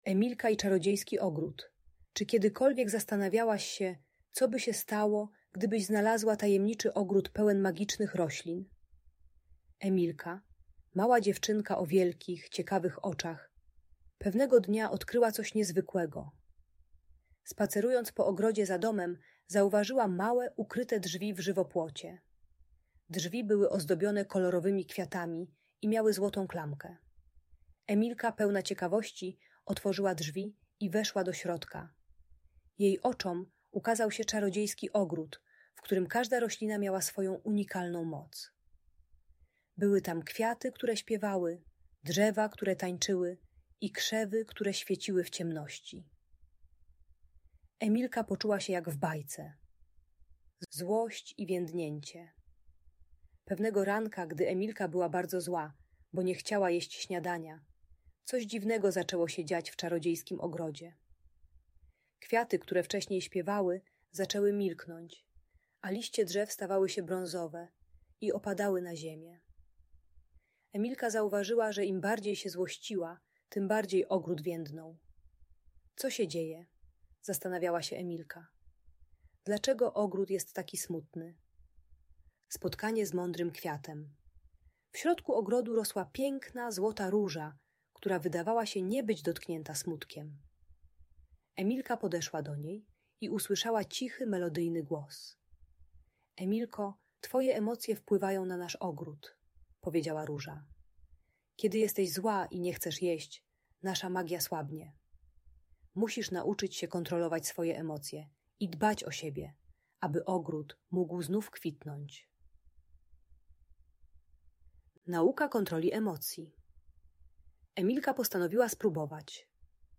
Emilka i Czarodziejski Ogród - Magiczna Historia - Audiobajka